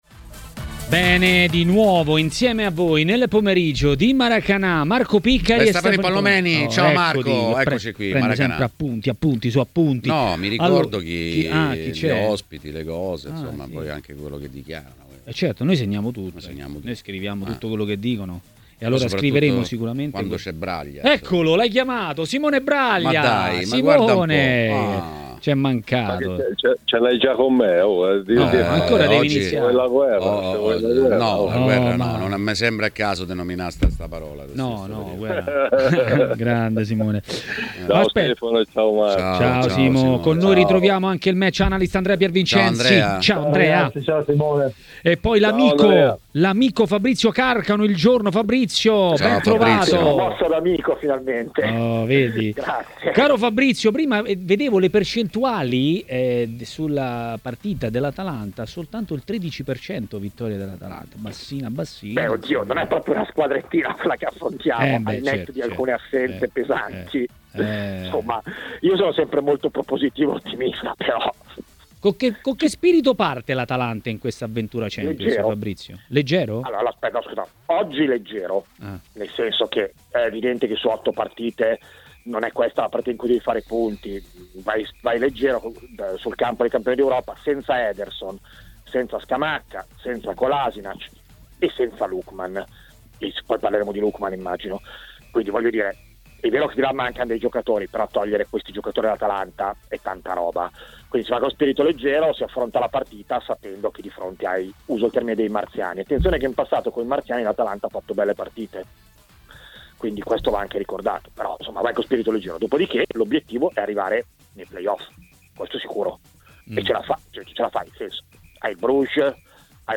Ospite di TMW Radio, durante Maracanà, è stato l'ex portiere Simone Braglia.